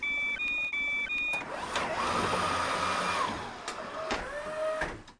AbfahrtHaltestelleTourbus.wav